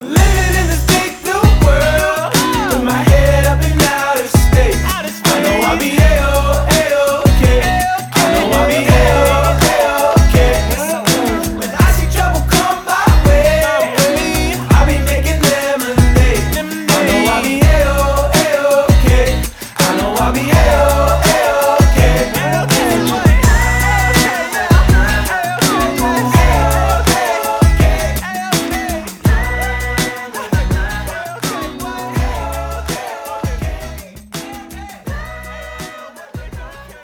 • Качество: 320, Stereo
поп
позитивные
мужской голос
веселые